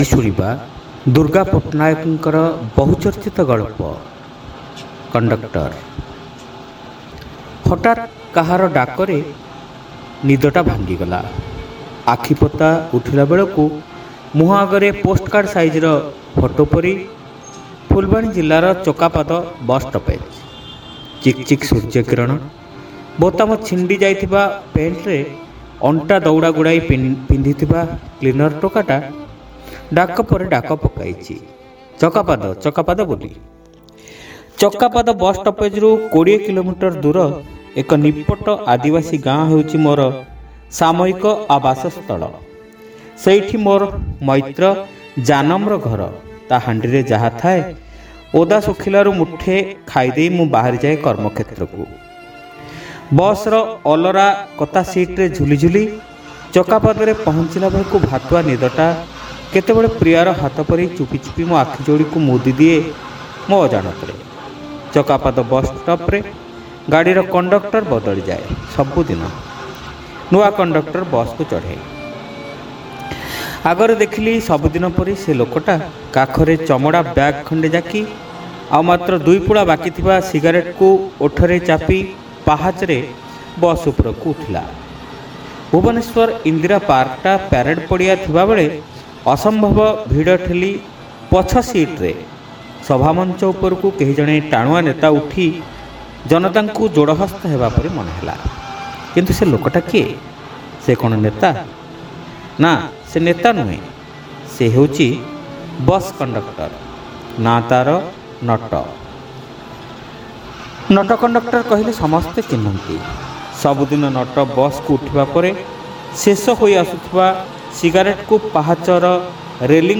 ଶ୍ରାବ୍ୟ ଗଳ୍ପ : କଣ୍ଡକ୍ଟର (ପ୍ରଥମ ଭାଗ)